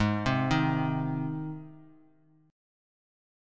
Ab Chord
Listen to Ab strummed